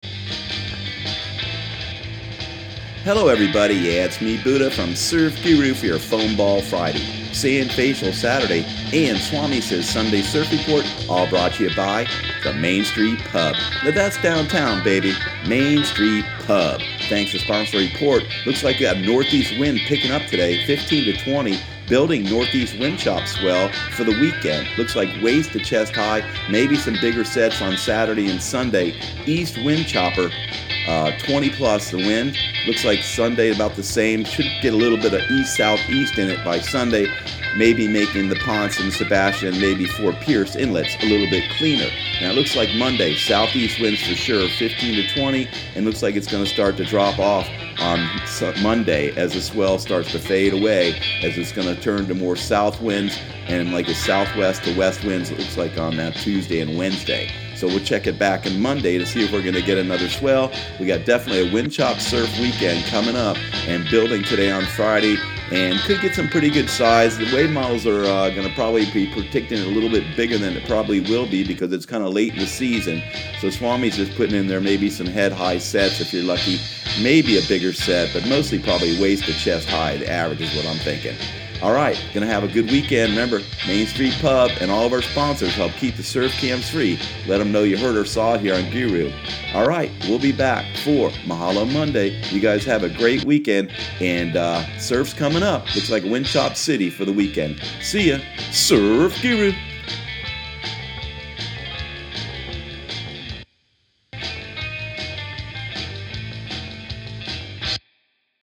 Surf Guru Surf Report and Forecast 04/20/2018 Audio surf report and surf forecast on April 20 for Central Florida and the Southeast.